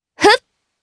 Lewsia_B-Vox_Attack2_jp.wav